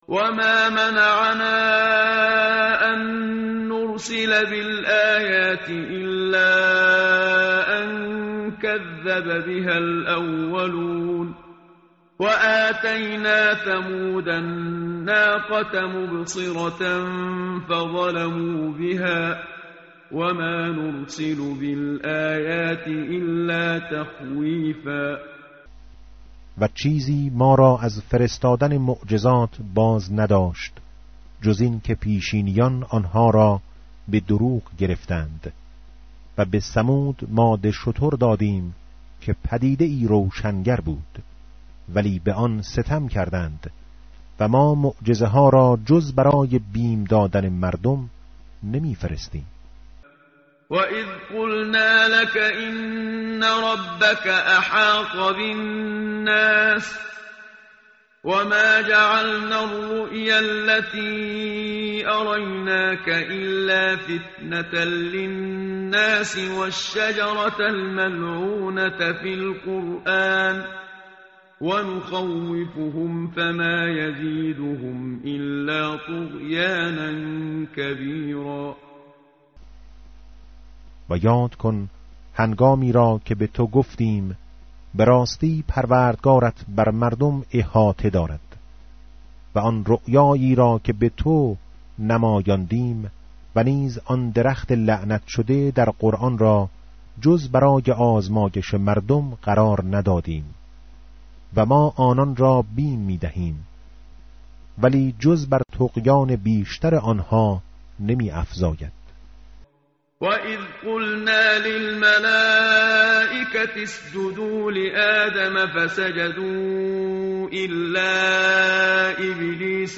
متن قرآن همراه باتلاوت قرآن و ترجمه
tartil_menshavi va tarjome_Page_288.mp3